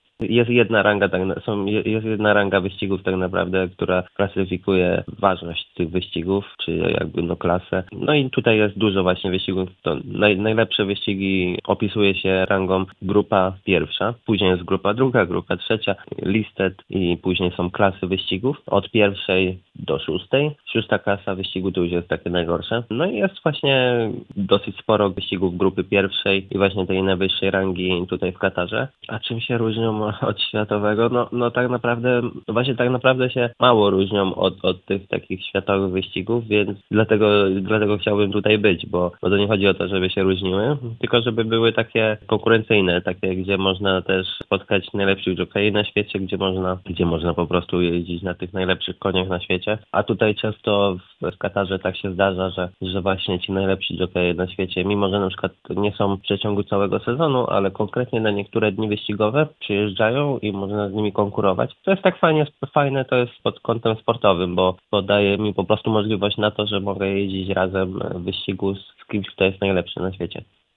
Cała rozmowa w audycji „Sportowy kwadrans” w poniedziałek o 15:30!